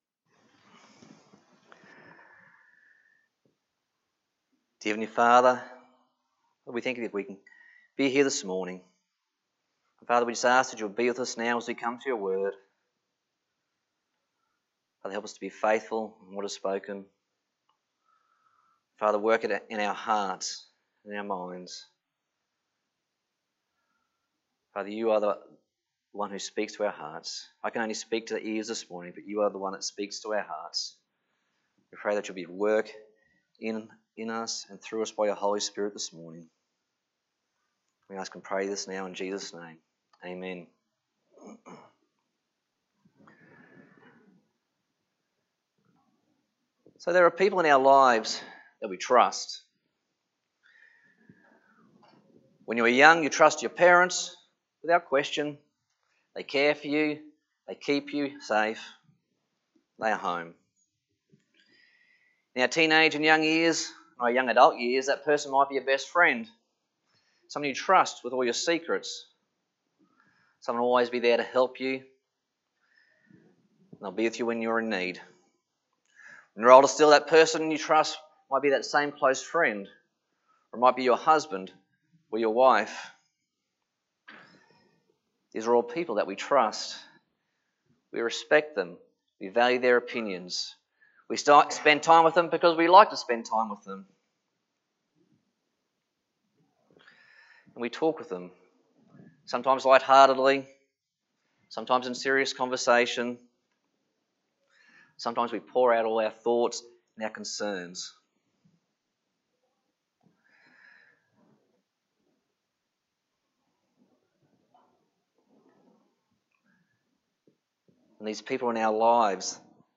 Passage: Genesis 15:1-6 Service Type: Sunday Morning